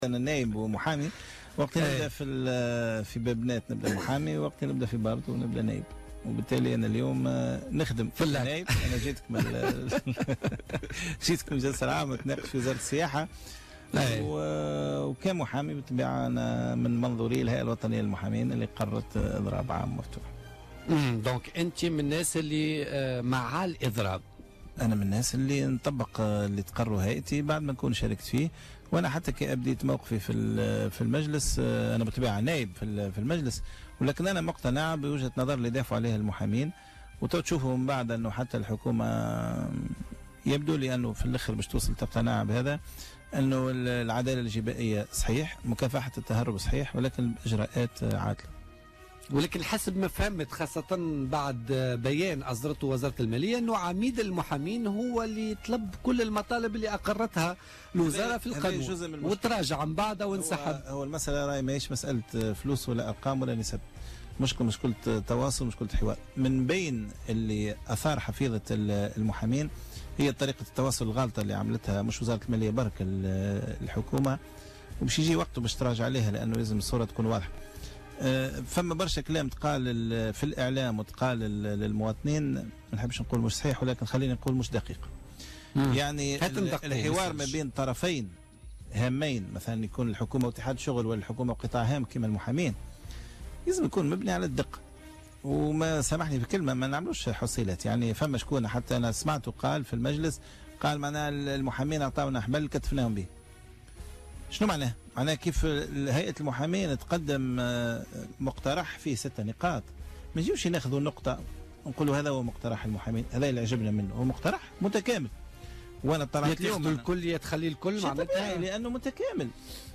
أكد النائب بمجلس نواب الشعب والقيادي في حركة النهضة سمير ديلو ضيف برنامج بوليتكا لليوم الاثنين 5 ديسمبر 2016 أنه دخل في إضراب عام مفتوح ضمن تحرك احتجاجي يقوم به المحامون وذلك بوصفه محاميا.